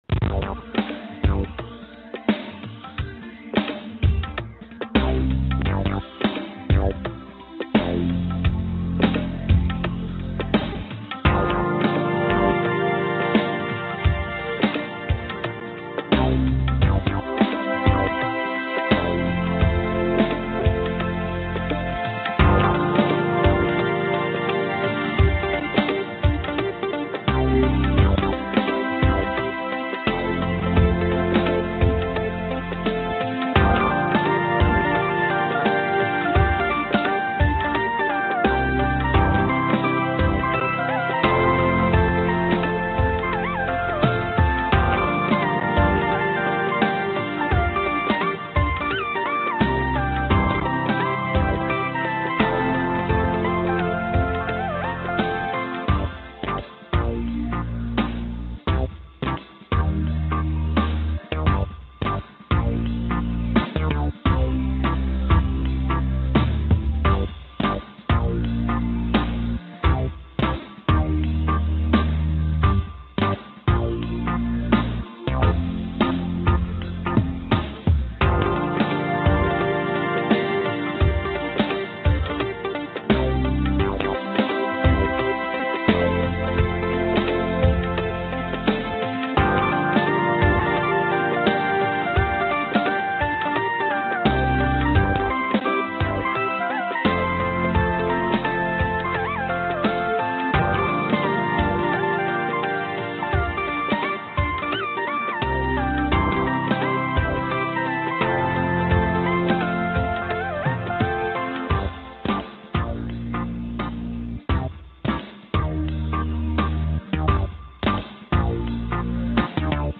HP Hold Music (2020).wav